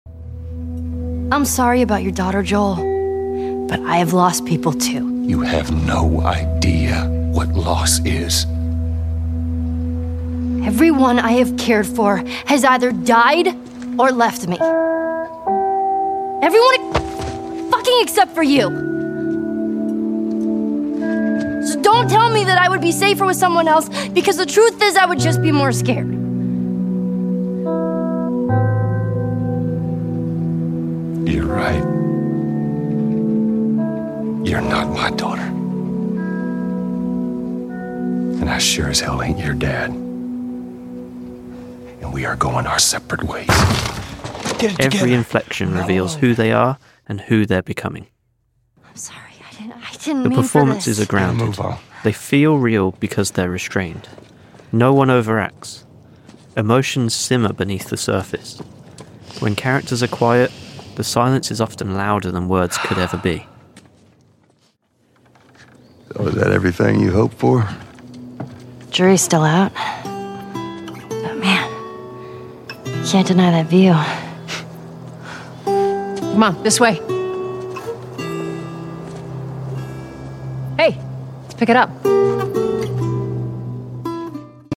It lets performance, sound, and silence share the stage.
The performances are grounded. They feel real because they’re restrained. No one overacts.